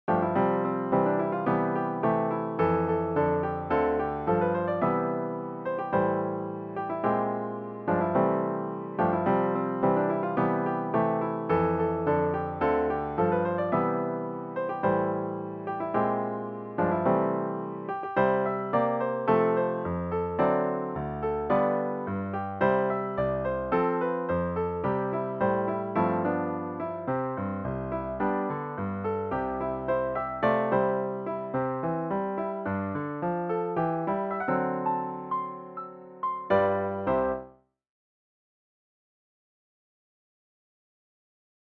PIANO SOLO Patriotic, American Music Skill level
DIGITAL SHEET MUSIC - PIANO SOLO